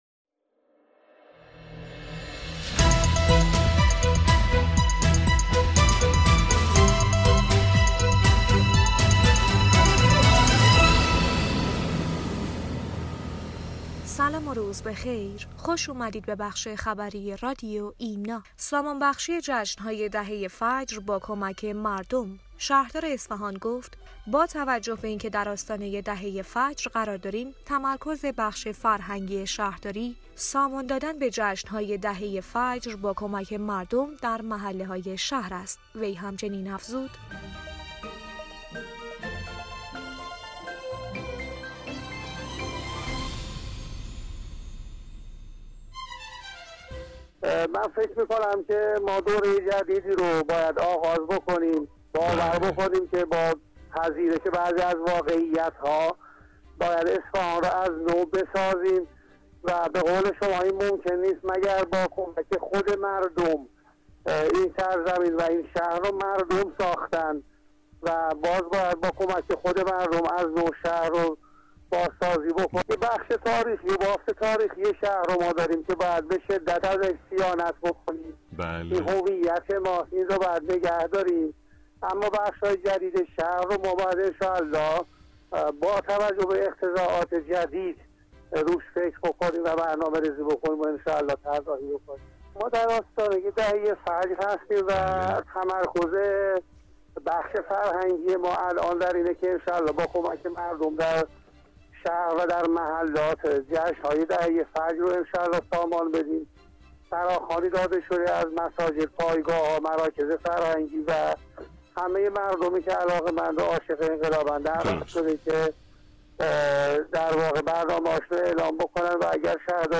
بسته خبری رادیو ایمنا/